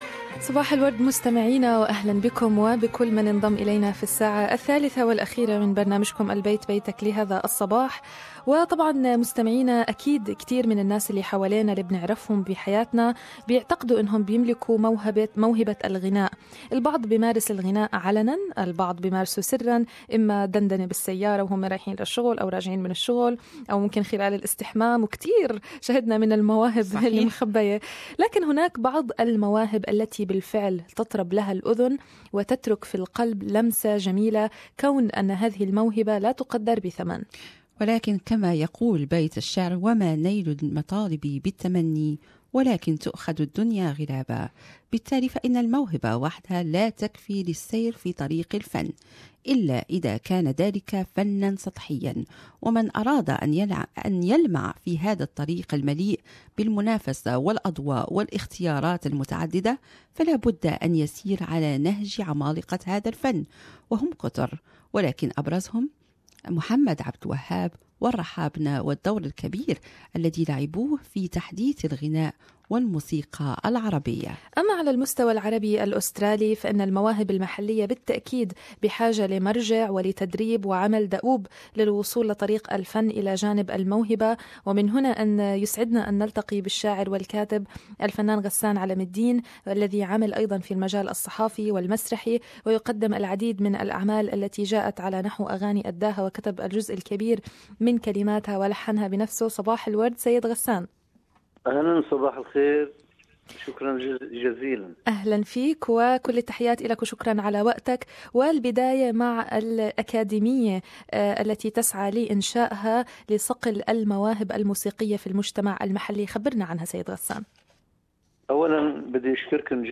للاستماع للمقابلة يرجى الضغط على المقطع الصوتي أعلى الصفحة استمعوا هنا الى البث المباشر لإذاعتنا و لإذاعة BBC أيضا شارك